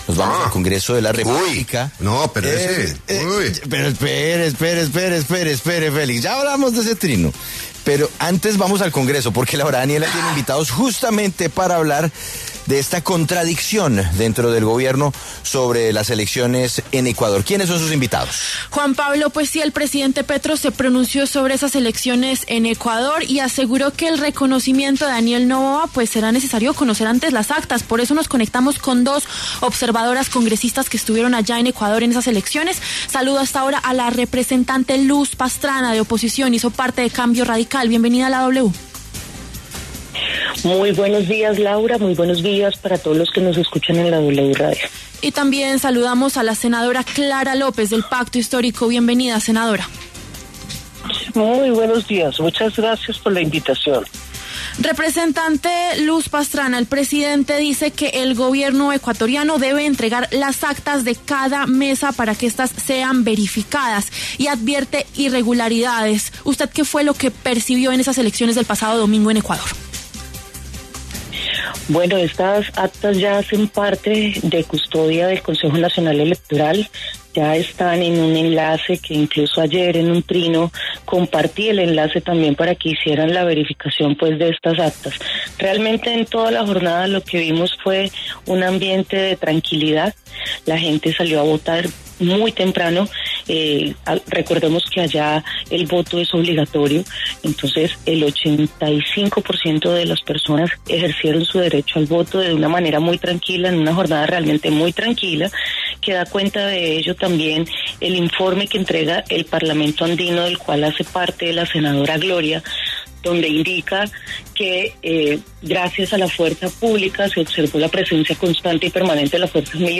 Debate congresistas: ¿Hay doble rasero del Gobierno Petro sobre elecciones en Ecuador y Venezuela?